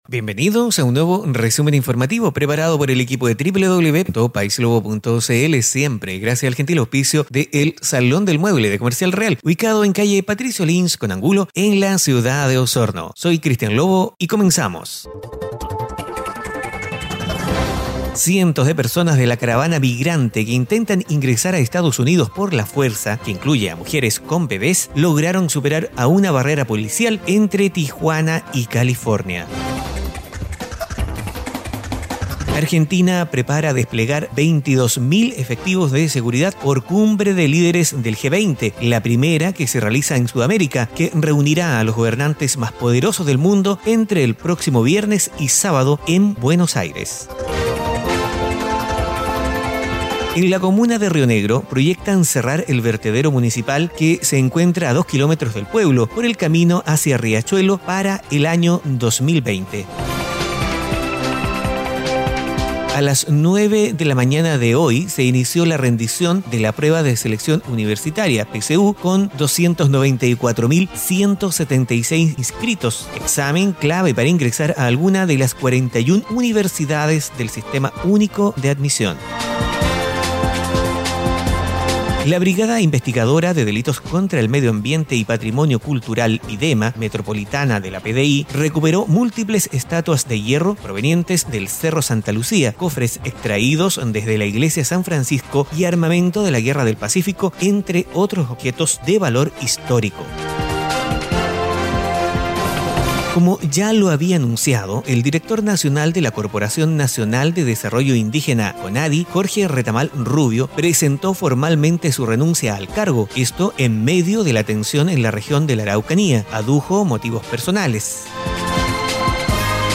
🔴 Resumen Informativo - Lunes 26 de Noviembre de 2018